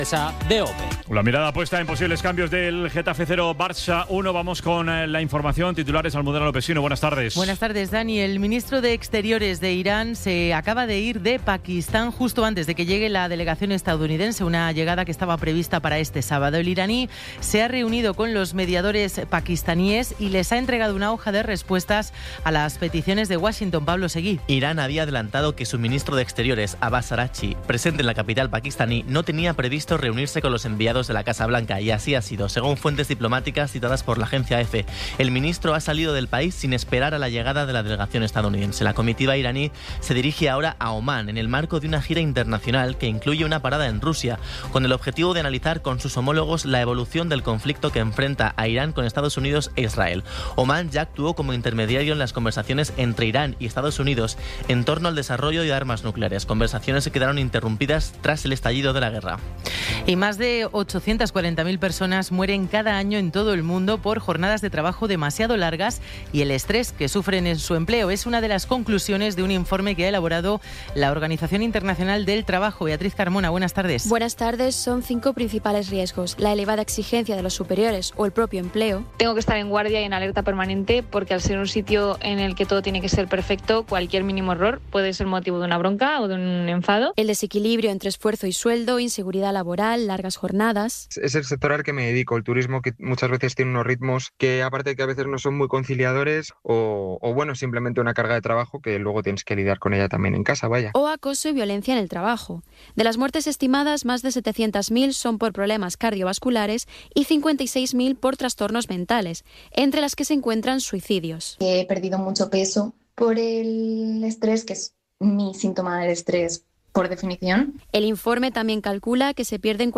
Resumen informativo con las noticias más destacadas del 25 de abril de 2026 a las cinco de la tarde.